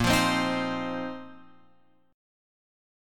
A#sus2sus4 chord